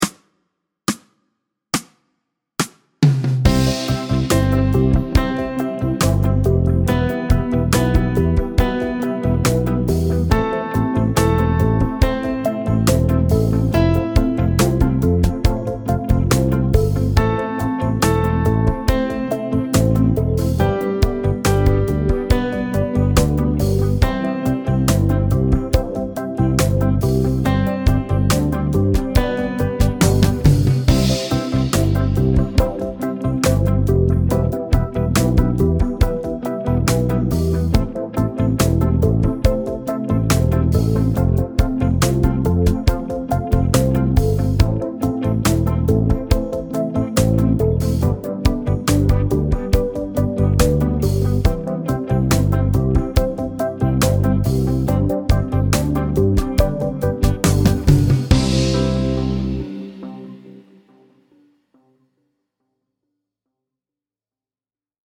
Medium C instr (demo)